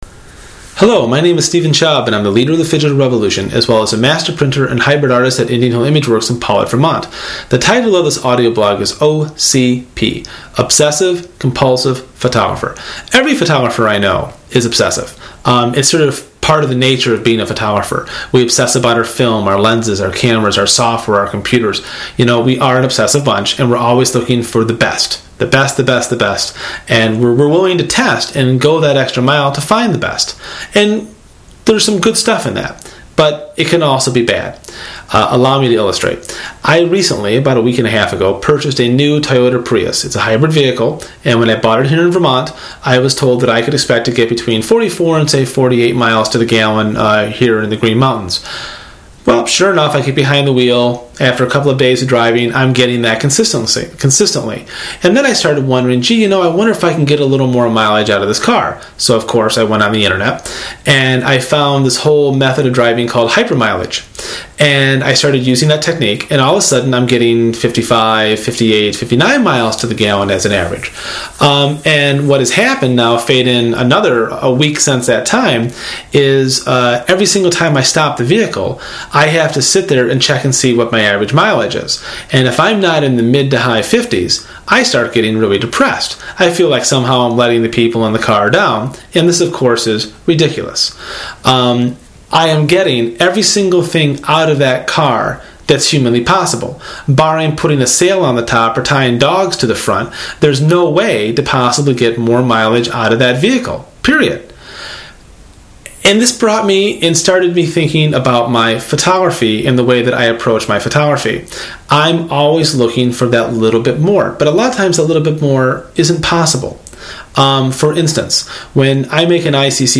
This audio blog attempts to provide a bit of breather room and hopefully some ideas on how to control your OCP (obsessive complusive photographic disorder) before it is too late!